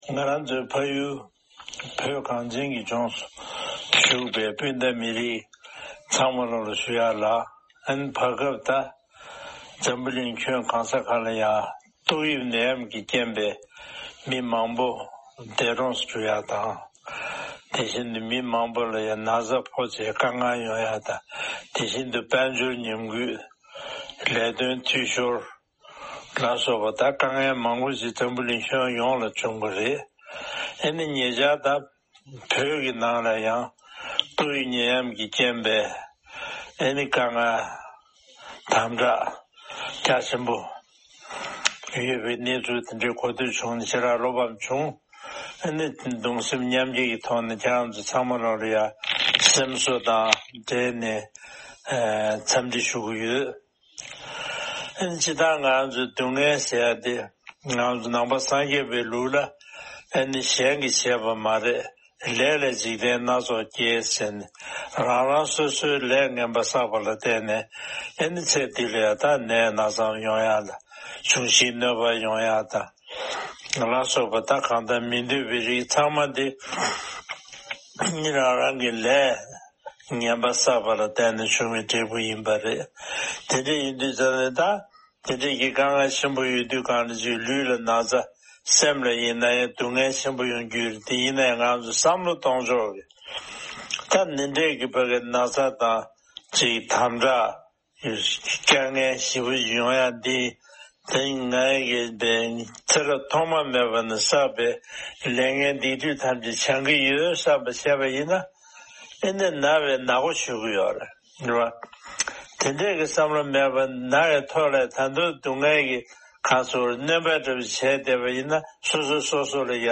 ༄༅། །དཔལ་ལྡན་ས་སྐྱ་གོང་མ་ཁྲི་ཆེན་རྡོ་རྗེ་འཆང་མཆོག་གིས་བོད་ཀྱི་རྒྱལ་ས་ལྷ་ས་གཙོས་པའི་ས་ཁུལ་ཁག་ནང་ཏོག་དབྱིབས་ནད་ཡམས་ཀྱི་བཀག་རྒྱའི་དམ་དྲག་འོག་དཀའ་སྡུག་མྱོང་བཞིན་པའི་མང་ཚོགས་ལ་དམིགས་བསལ་གྱི་བཀའ་སློབ་དང་ཐུགས་སྨོན་སྐྱབས་འཇུག་བསྩལ་བ་ཞིག་གསན་རོགས་གནང་།།